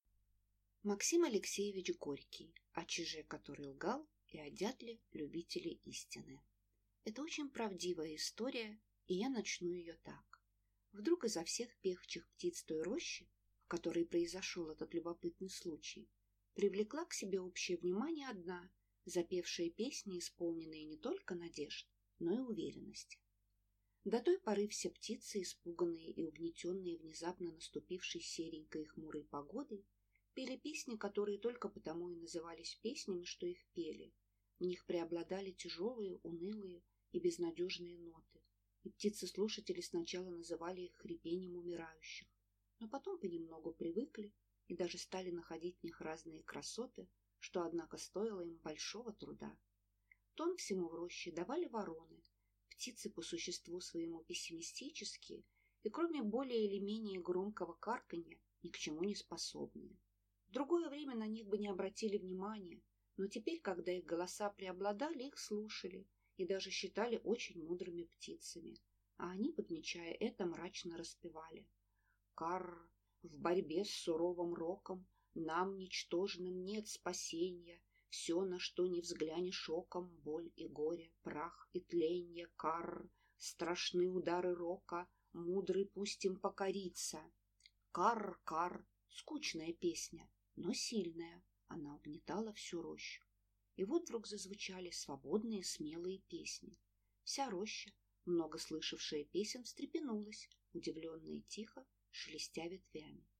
Аудиокнига О чиже, который лгал, и о дятле – любителе истины | Библиотека аудиокниг